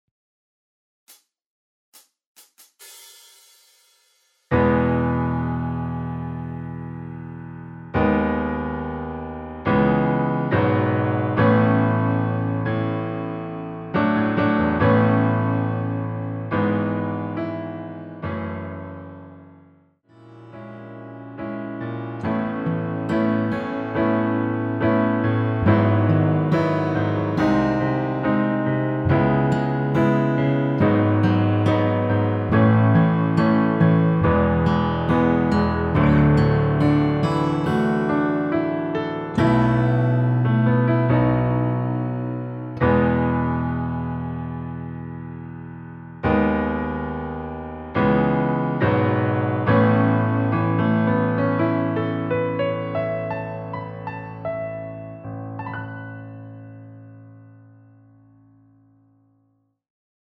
1절끝(때문이란 걸) 다음 마지막(그대를 만나고)로 진행 됩니다.
전주없이 노래가시작되는곡이라 카운트 만들어 놓았습니다.
앞부분30초, 뒷부분30초씩 편집해서 올려 드리고 있습니다.
중간에 음이 끈어지고 다시 나오는 이유는